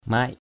/maɪʔ/ mac MC [A, 387] [Bkt.]